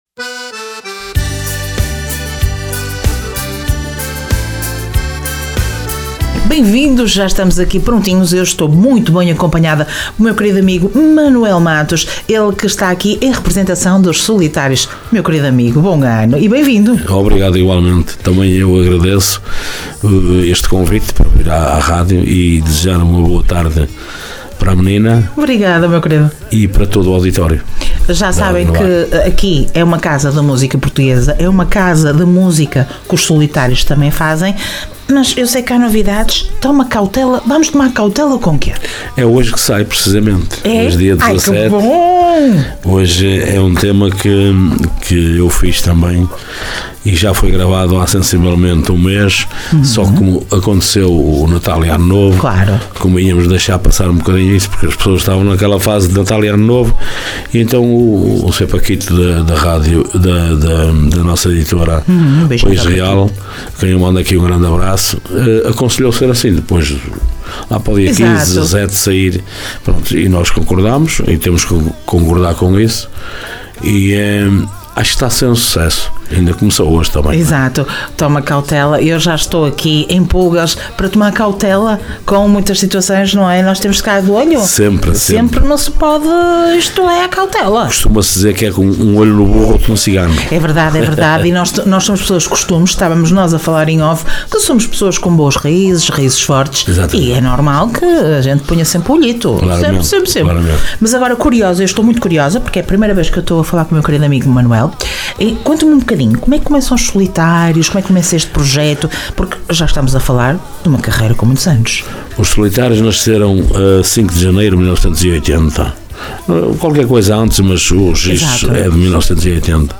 Entrevista Os Solitários
ENTREVISTA-SOLITARIOS-2025.mp3